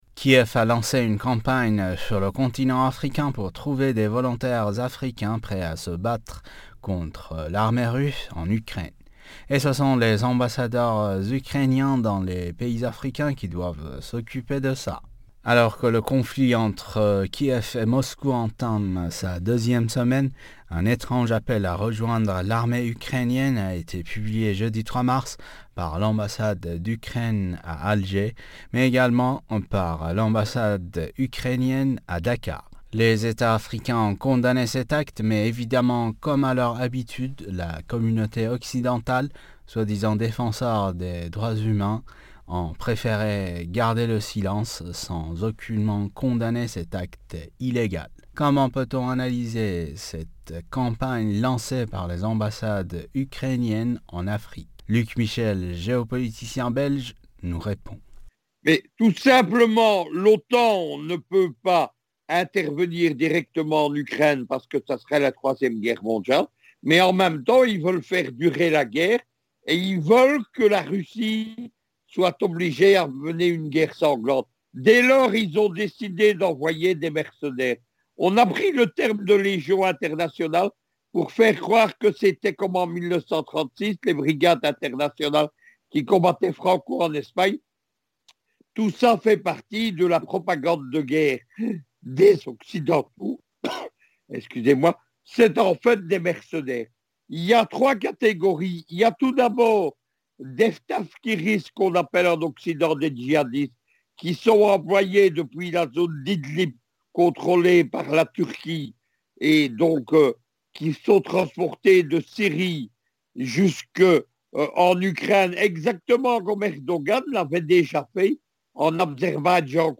géopoliticien belge nous répond.